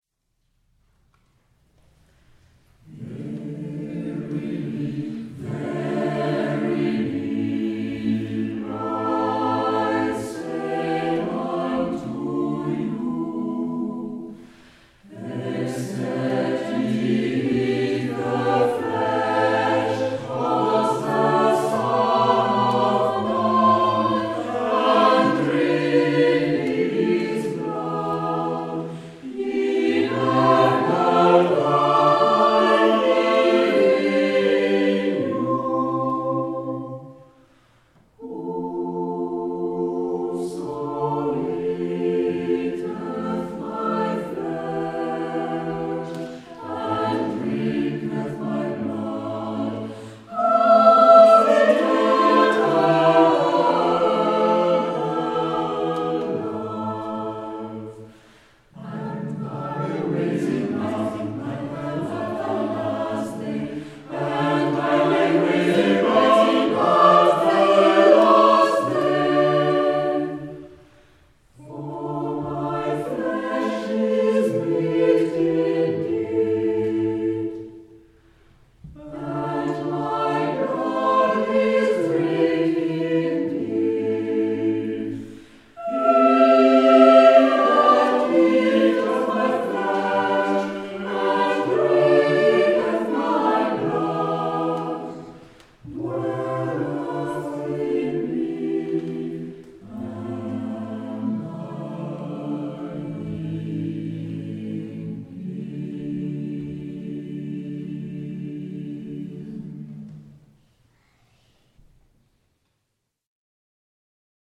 Concert donné
2015_Montheron_Verily.mp3